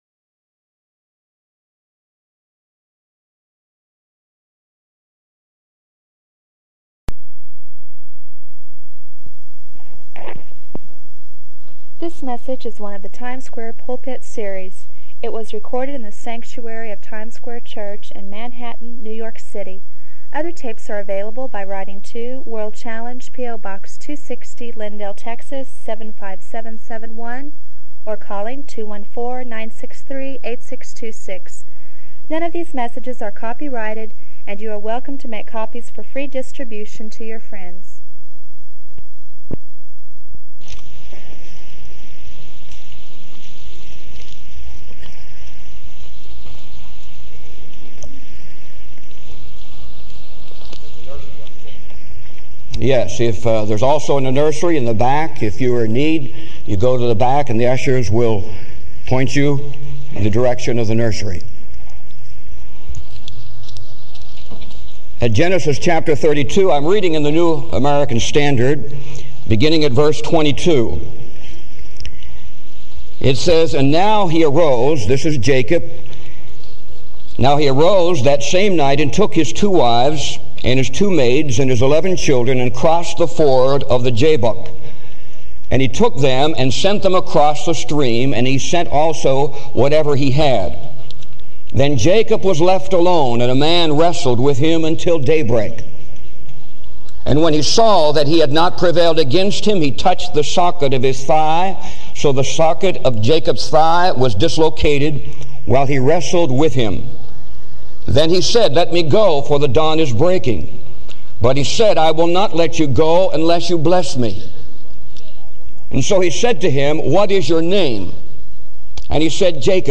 This sermon challenges believers to embrace God’s work in their lives, laying aside sin and self-reliance to walk in the fullness of His blessing.